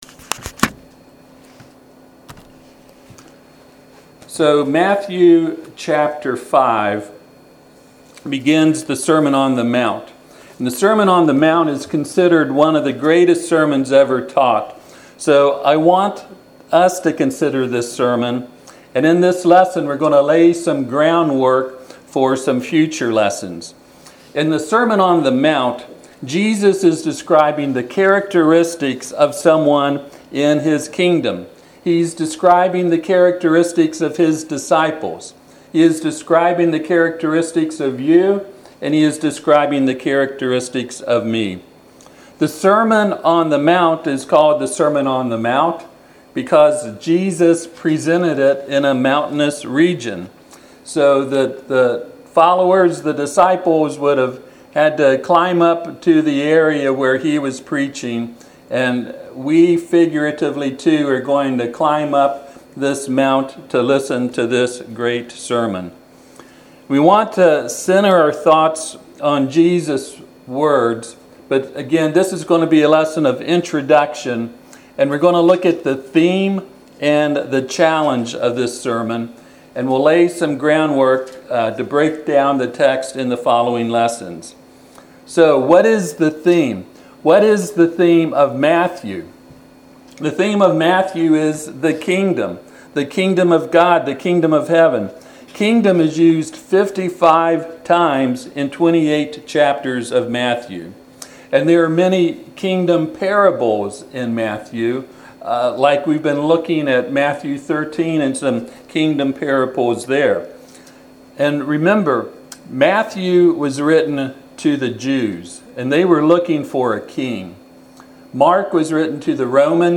Passage: Matthew 5:17-20 Service Type: Sunday AM